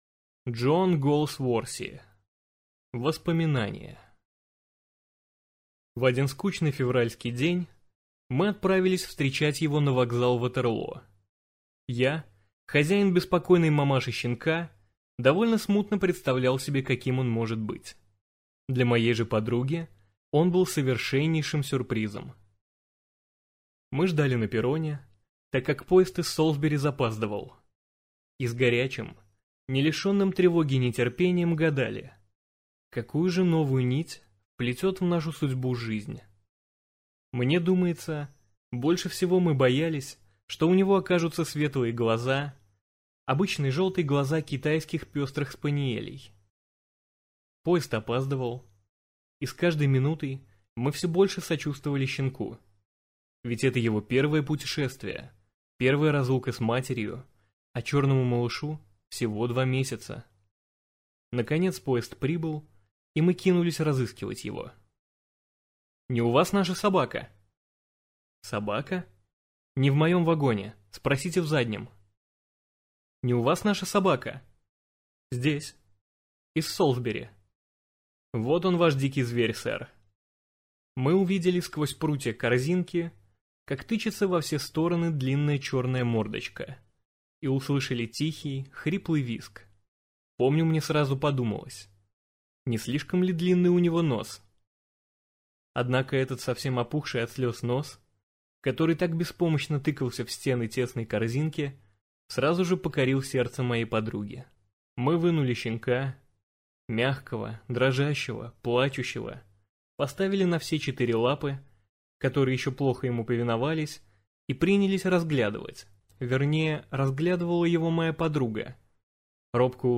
Аудиокнига Воспоминания | Библиотека аудиокниг